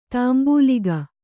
♪ tāmbūliga